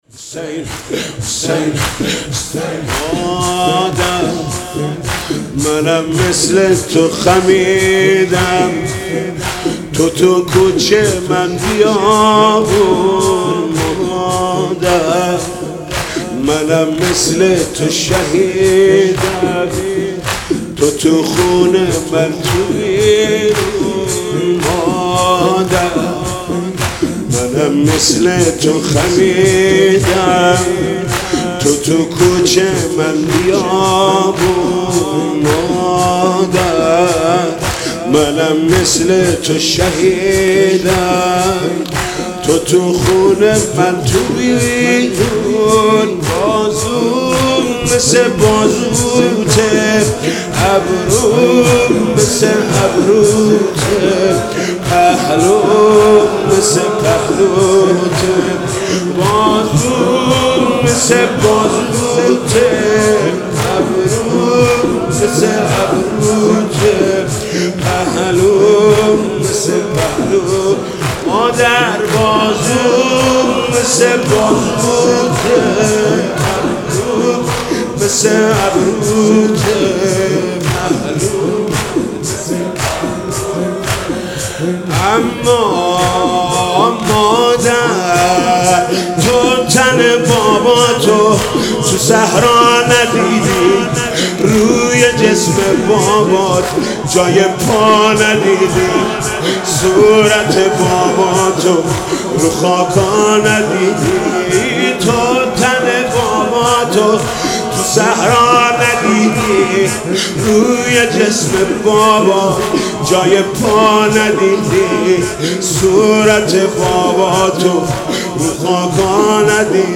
مناسبت : شهادت حضرت فاطمه زهرا سلام‌الله‌علیها
مداح : محمود کریمی قالب : زمینه